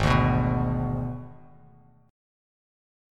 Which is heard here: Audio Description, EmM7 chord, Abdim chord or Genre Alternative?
Abdim chord